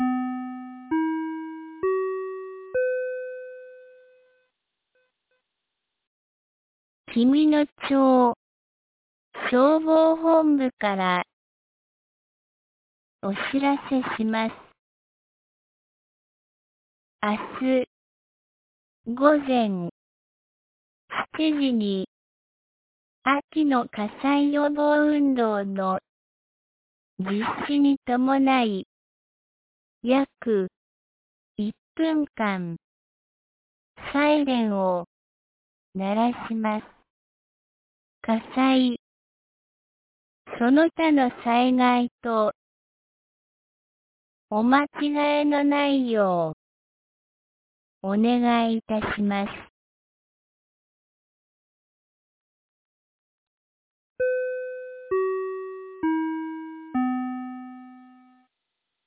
2025年11月08日 17時06分に、紀美野町より全地区へ放送がありました。